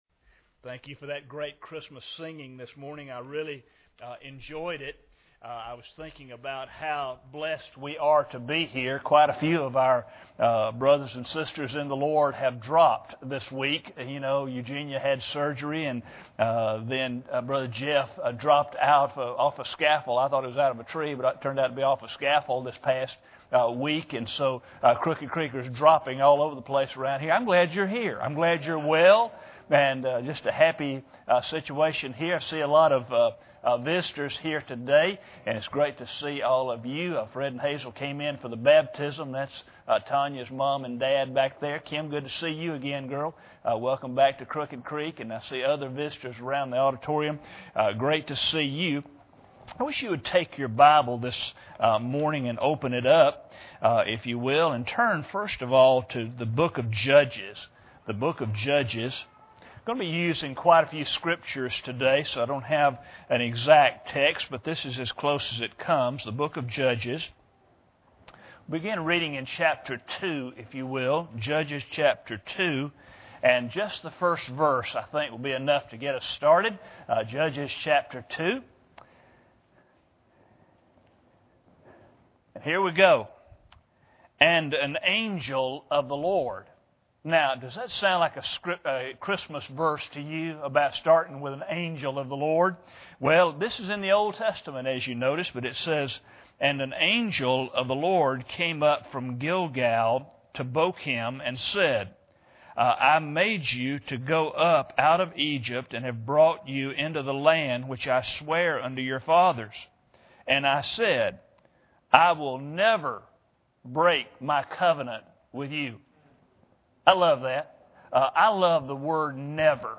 Judges 2:1 Service Type: Sunday Morning Bible Text